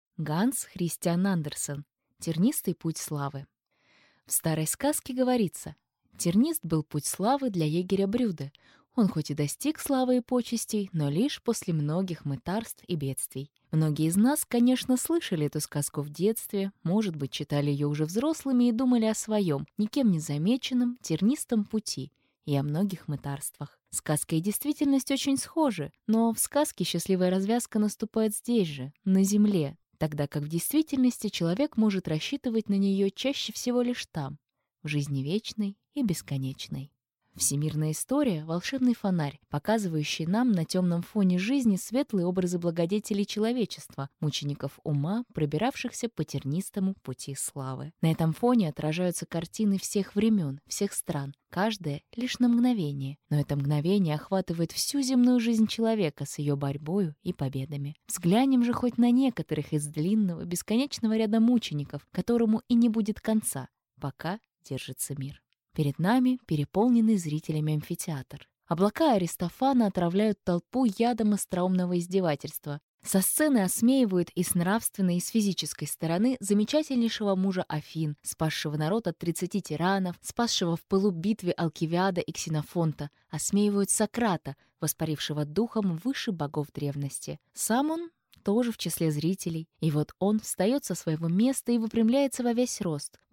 Аудиокнига Тернистый путь славы | Библиотека аудиокниг